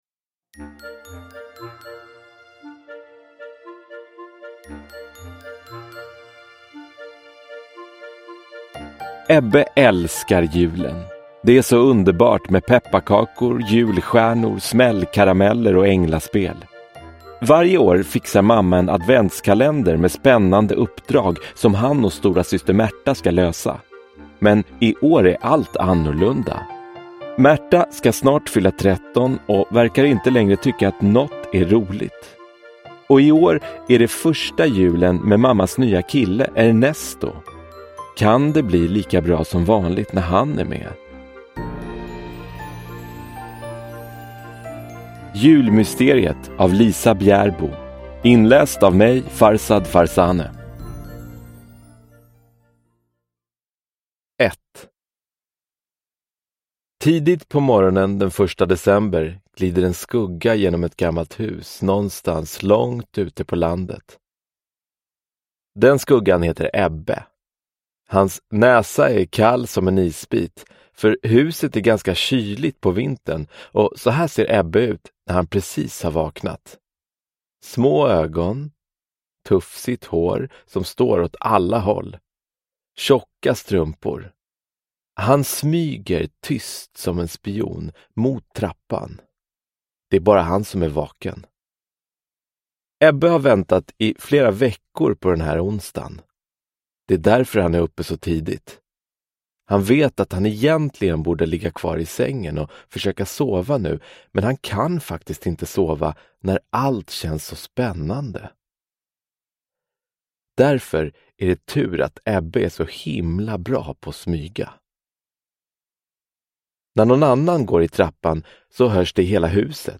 Julmysteriet – Ljudbok – Laddas ner